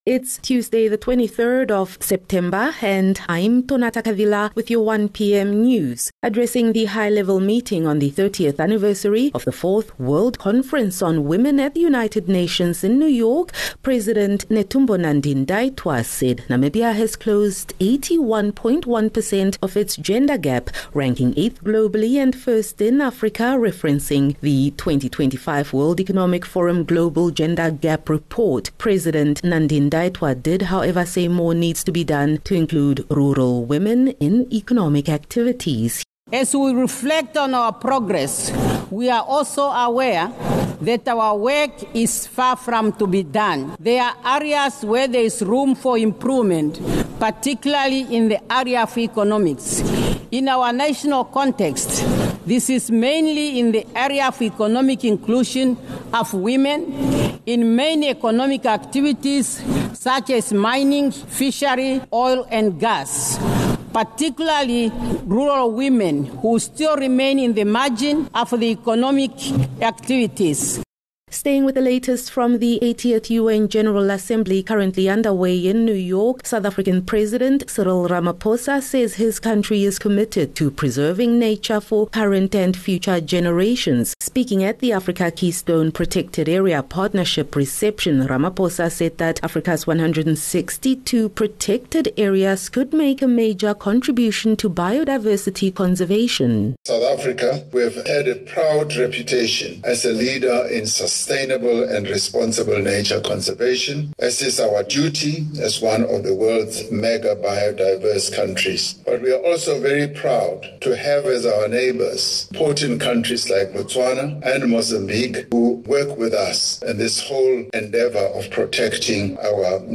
23 Sep 23 September - 1 pm news